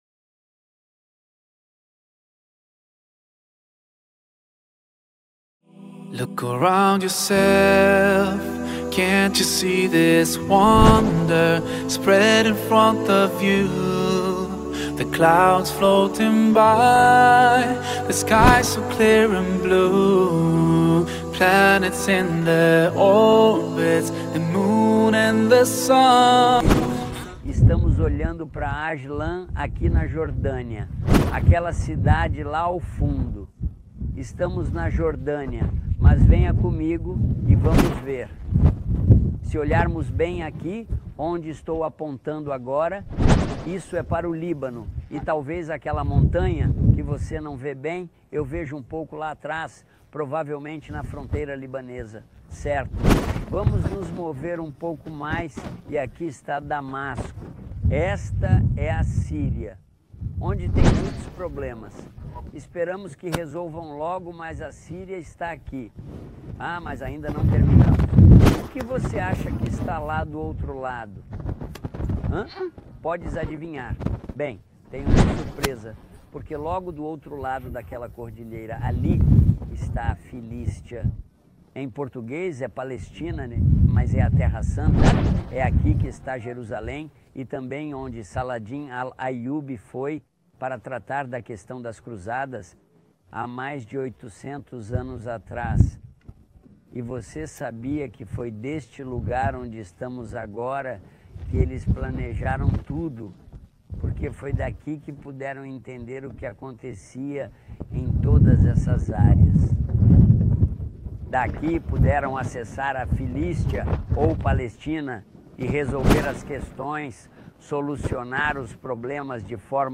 filmed in the backdrop of scenic landscapes and historic places of Jordan. In this episode, he explains the benefits of being a Muslim.